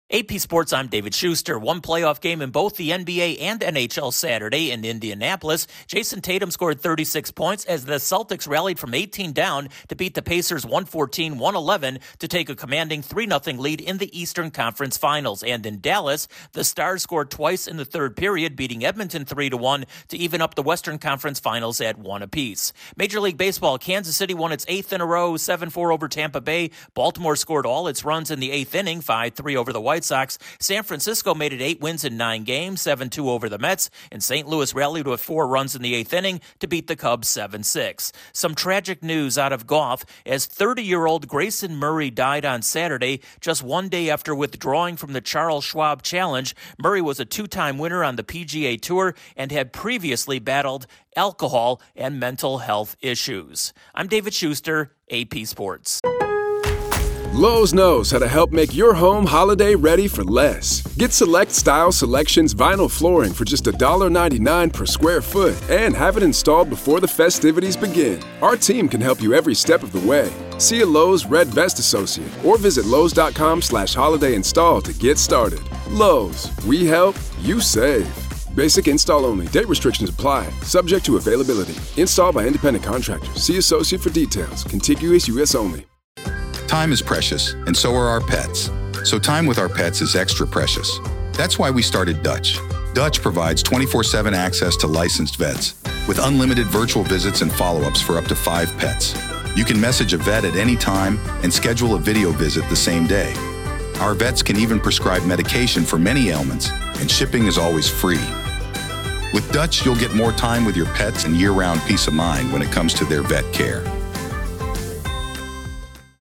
One game in both the NBA and NHL playoffs Saturday and both are close contests, eight seems to be a magic number in MLB and a tragic day in the world of golf. Correspondent